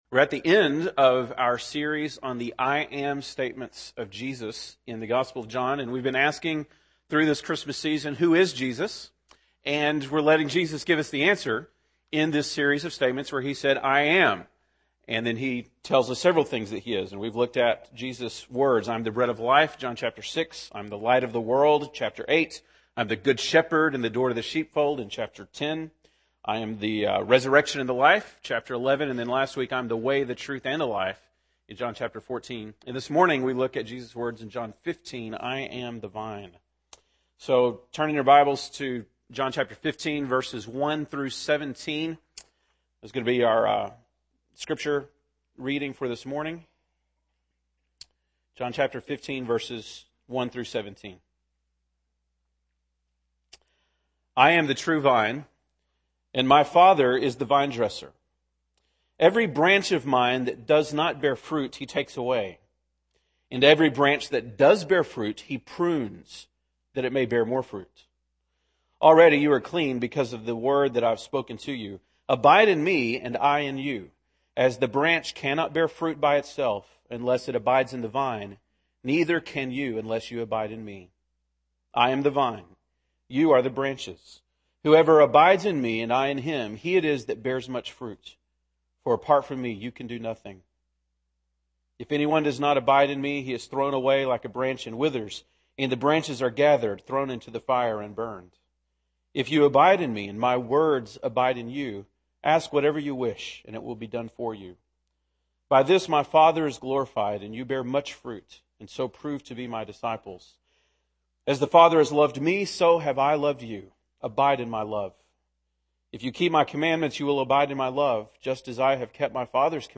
December 19, 2010 (Sunday Morning)